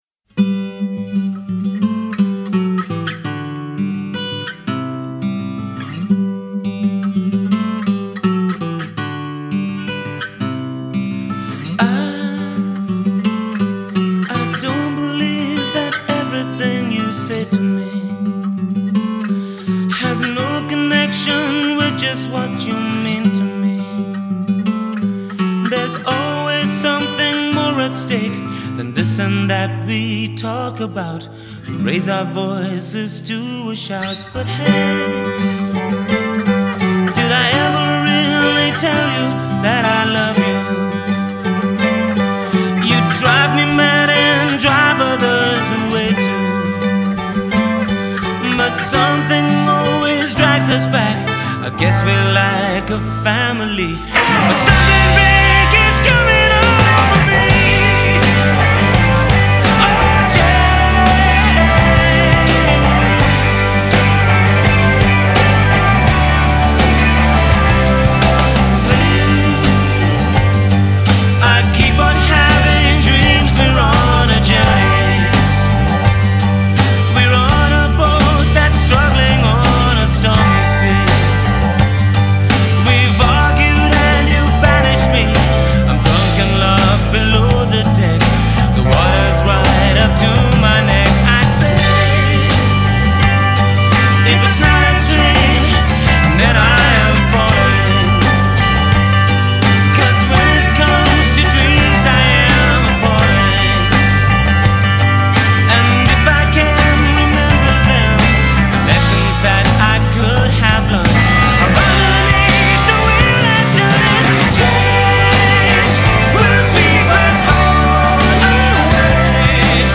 It’s as close as we ever got to a power ballad .